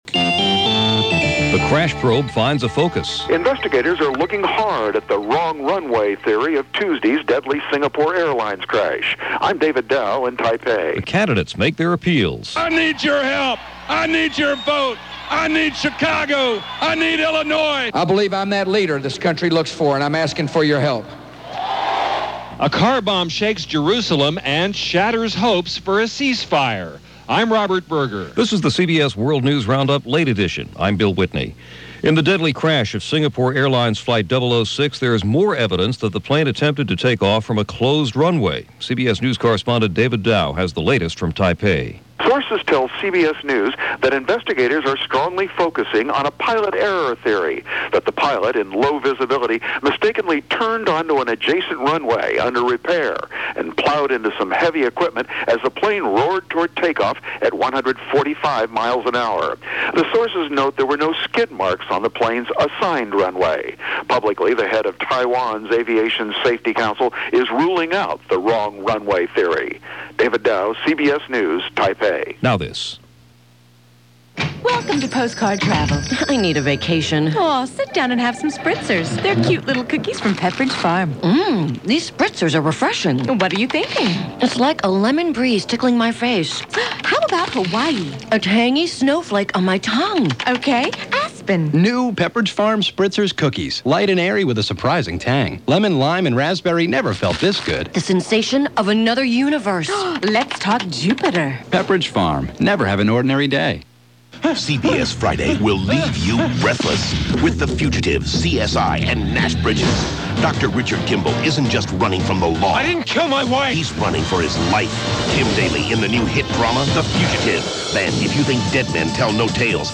And that was a small slice of what went on, this November 2, 2000 as reported by the CBS World News Roundup: Late Edition